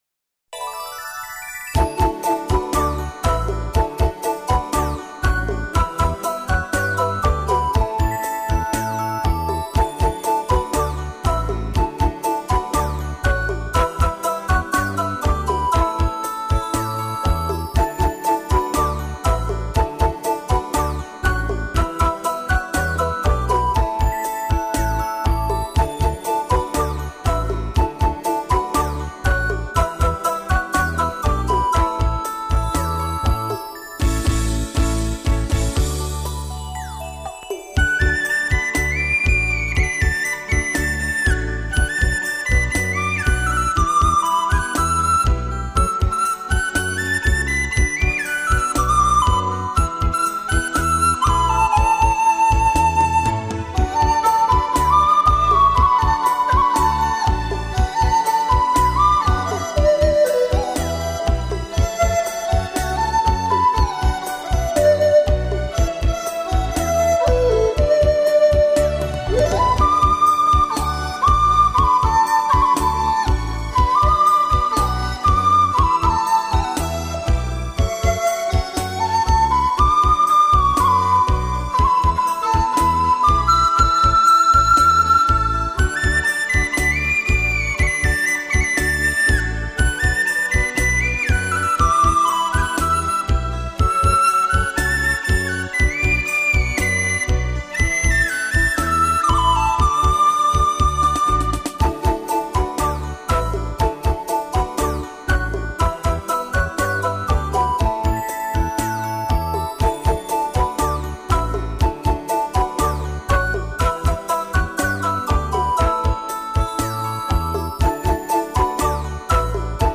这系列编曲风格新颖别致，凄婉而不哀伤，流畅多变的节奏，巧妙细腻的配器，丝丝入扣的演奏，描绘春风和